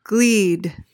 PRONUNCIATION: (gleed) MEANING: noun: A glowing coal.